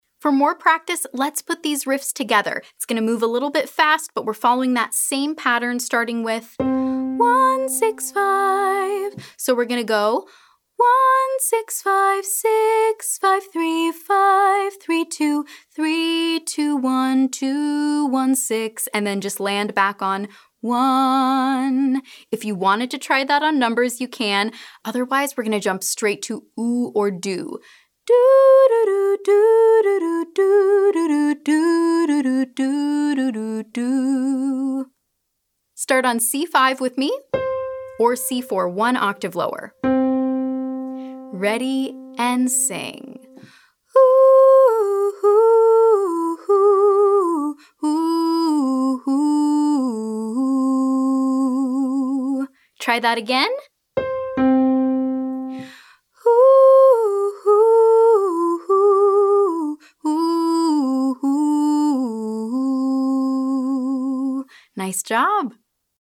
• descending pentatonic 3-note riffs